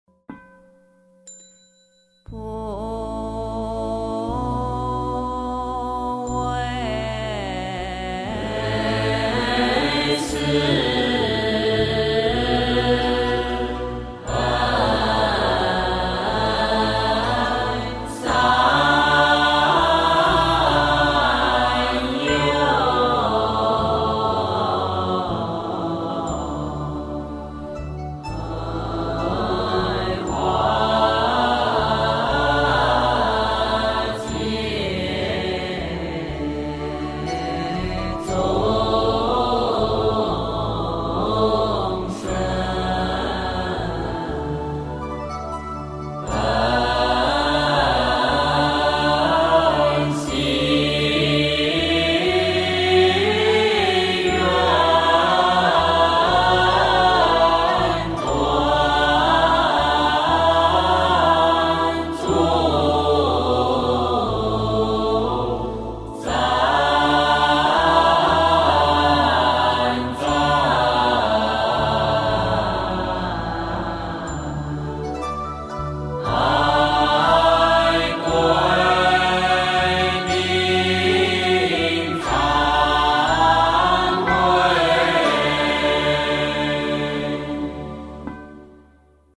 普为四恩三有--佛光山梵呗 经忏 普为四恩三有--佛光山梵呗 点我： 标签: 佛音 经忏 佛教音乐 返回列表 上一篇： 普贤四生九有--佛光山梵呗 下一篇： 地藏菩萨灭顶叶真言--未知 相关文章 作明佛母心咒--海涛法师 作明佛母心咒--海涛法师...